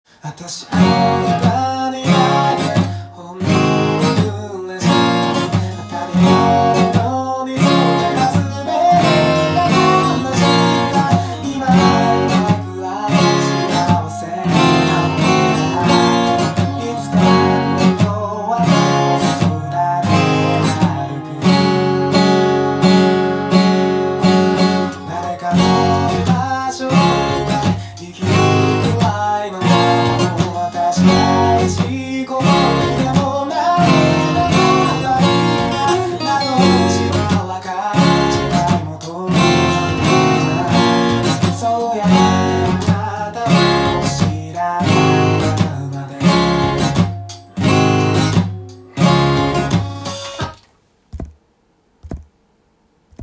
音源に合わせて譜面通り弾いてみました
CやF、G7など基本的なローコードが主になります。
付点８分音符や１６分音符
×表示のミュートが出てきます。
ハンマリングとプリングを使うカッコいいフレーズが入っていますので